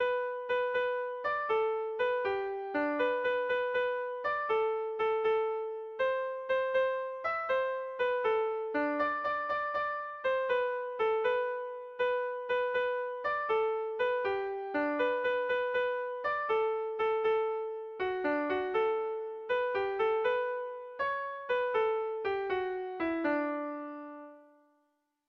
Sentimenduzkoa
Zortziko ertaina (hg) / Lau puntuko ertaina (ip)
ABAD